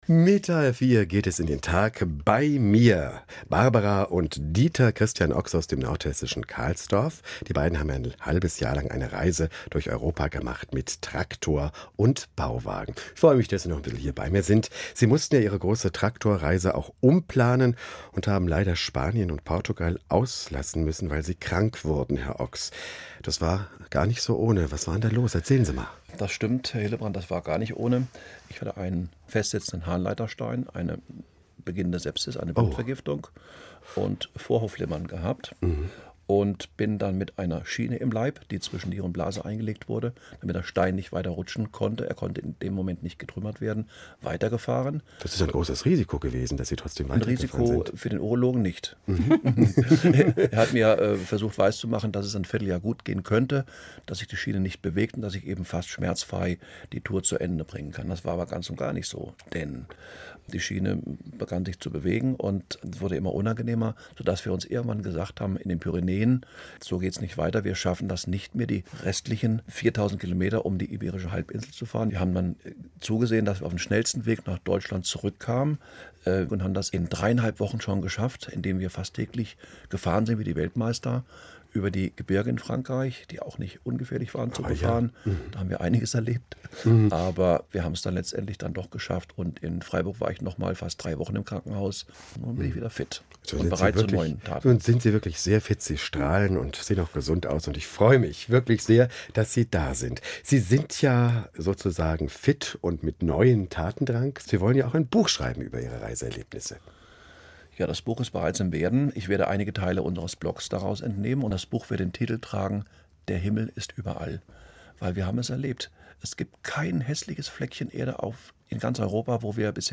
Sendung vom 19.11.2011